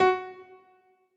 admin-amethyst-moon/b_piano2_v100l16o5fp.ogg at 3466ff99011ee6869ff81cbd2be56c43b1b9882d